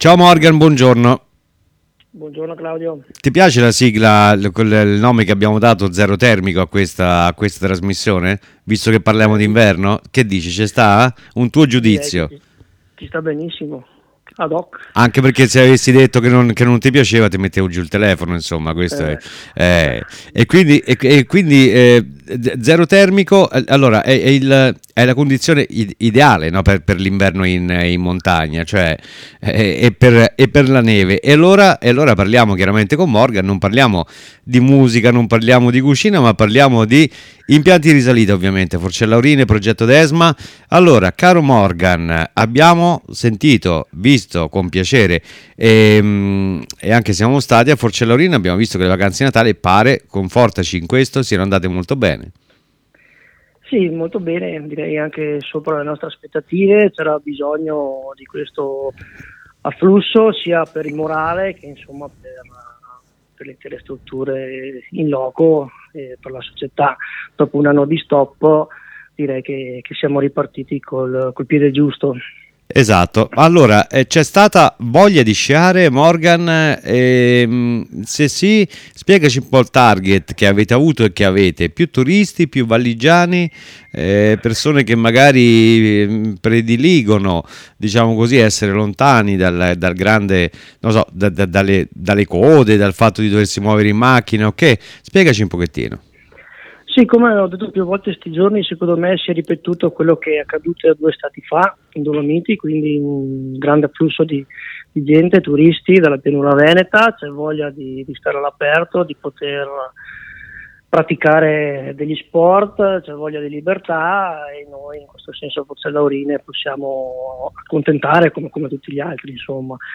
ZERO TERMICO E’ UN VIAGGIO ALLA SCOPERTA DEL NOSTRO TERRITORIO, DALLA VOCE DEI PROTAGONISTI. TURISMO, SPORT, EVENTI, CULTURA, INTRATTENIMENTO.